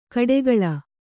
♪ kaḍegaḷa